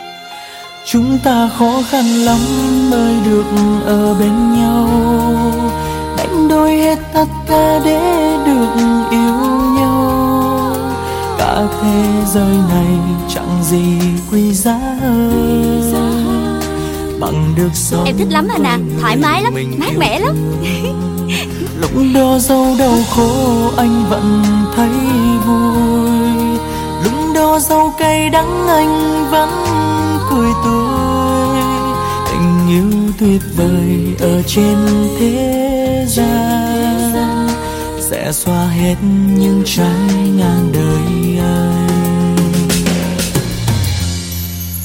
Thể loại: Nhạc Trẻ